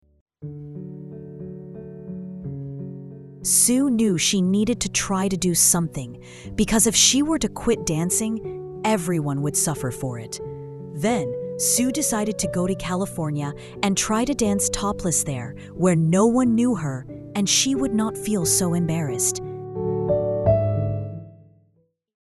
Secrets Are Out Now – How Girl Overcomes the World (Audiobook Edition) High-Quality Audio Recording Professional Narration Instant Audio Access Works on All Devices Listen to the sample clip below.